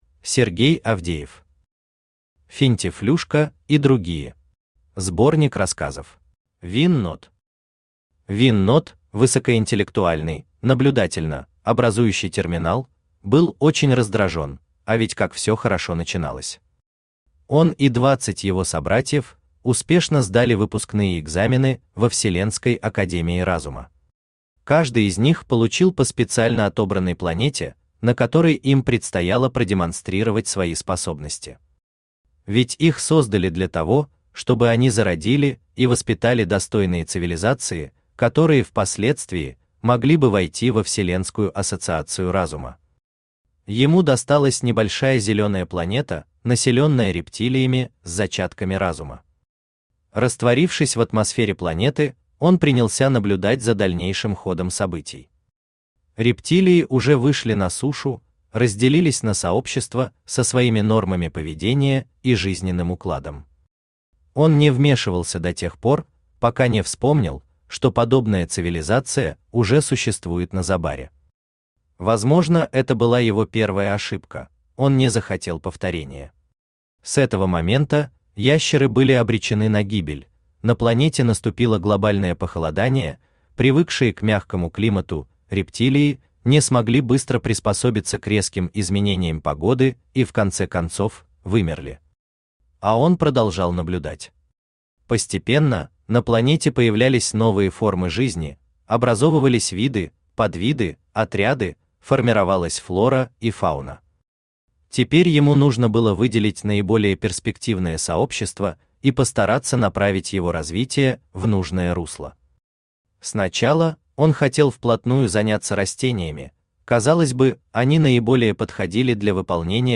Аудиокнига Финтифлюшка и другие! Сборник рассказов | Библиотека аудиокниг
Сборник рассказов Автор Сергей Дмитриевич Авдеев Читает аудиокнигу Авточтец ЛитРес.